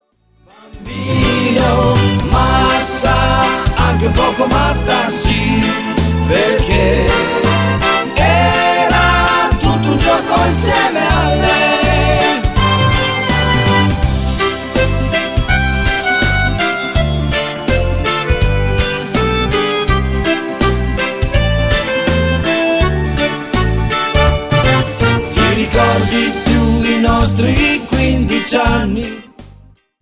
MODERATO  (5.12)